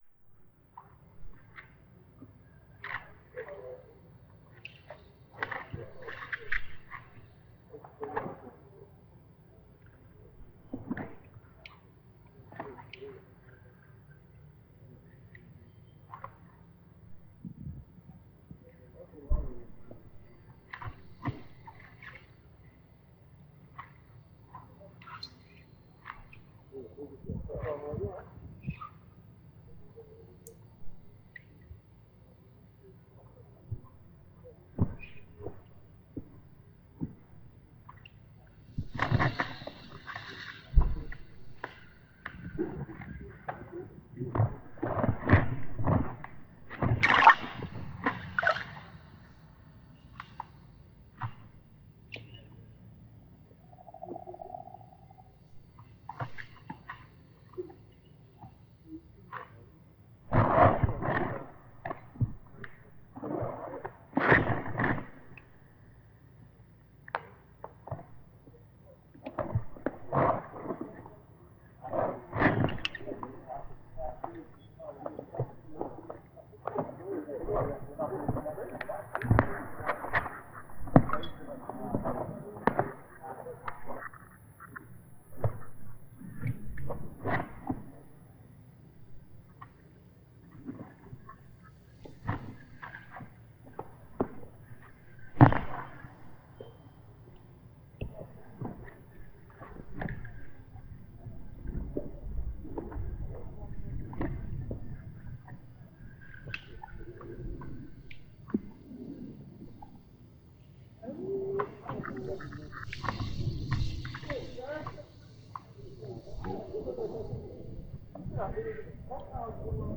03:51 MP3 Hydrophone recording, Brisbane floods, January 2011.
Helicopters flew overhead.